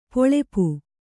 ♪ poḷep